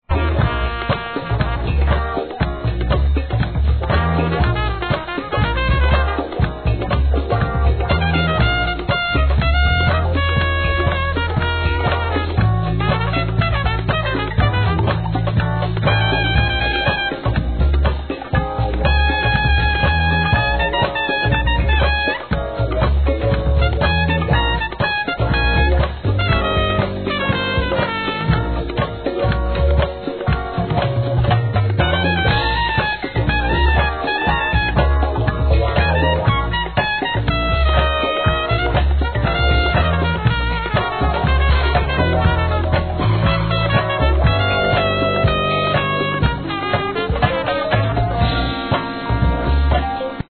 ¥ 770 税込 関連カテゴリ SOUL/FUNK/etc...
、ブレイクビーツ好きまでも唸らせる強力な内容の極上のジャズ・ブレイクス集！